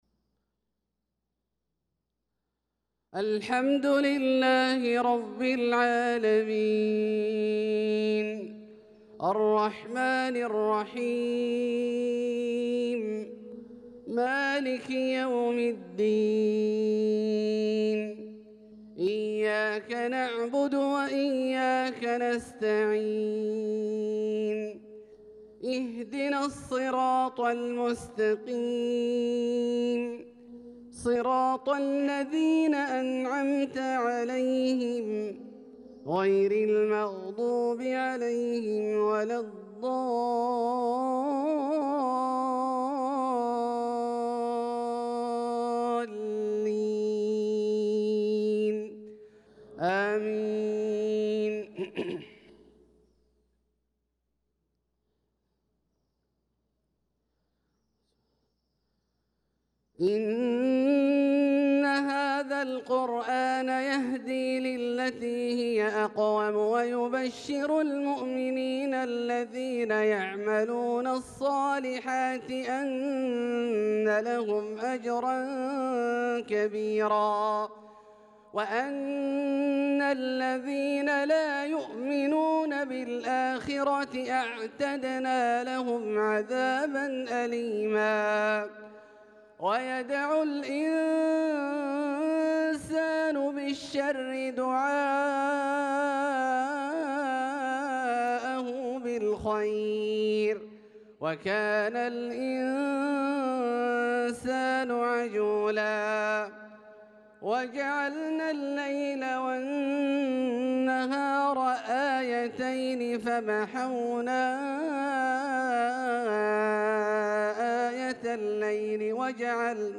صلاة المغرب للقارئ عبدالله الجهني 27 ذو القعدة 1445 هـ
تِلَاوَات الْحَرَمَيْن .